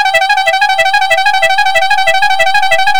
FIAMM TRIO 3-tónová fanfára s kompresorem, 12v
Stáhnout soubor fiamm_m4_trio_921967_12v_pneumatic_car_van-copia.wav
3-trubkové fanfáry poháněné vzduchovým kompresorem se střídavým zvukem. V sadě je malý kompresor a 3 trumpety, které zní v pořadí.